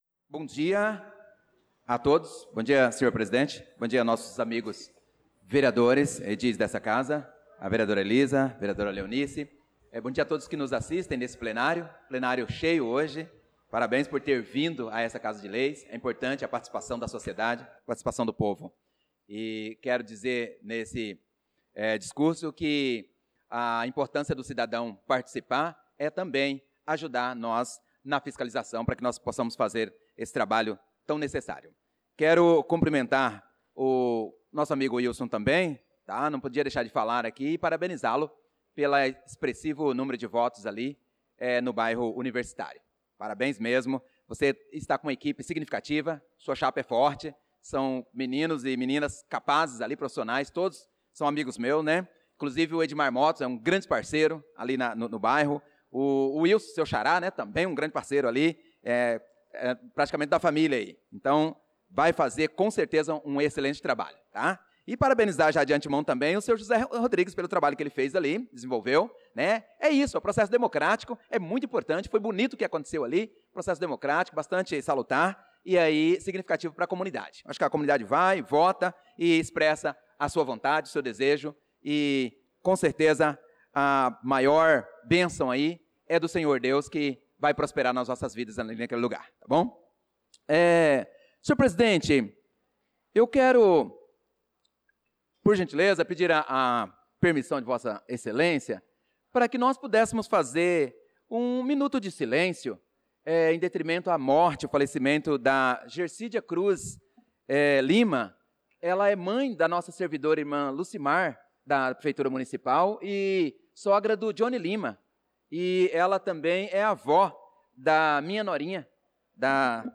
Pronunciamento do vereador Prof. Nilson na Sessão Ordinária do dia 28/04/2025